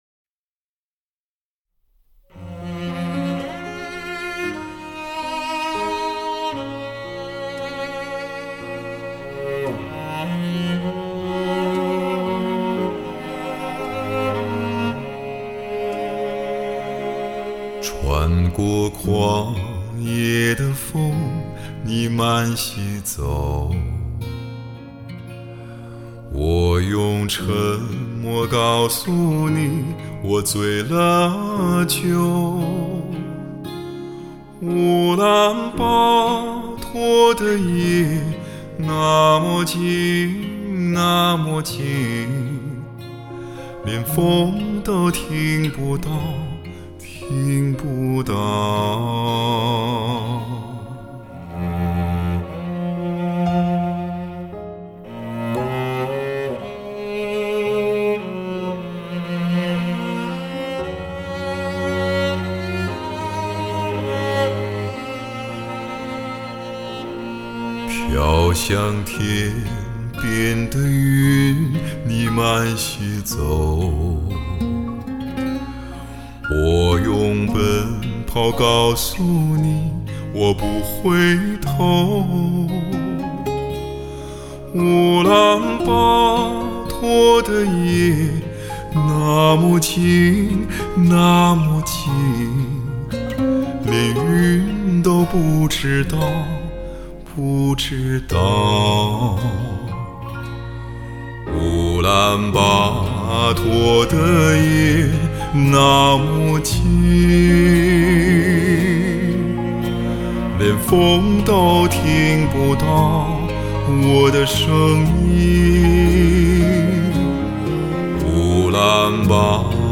专辑类别：流行音乐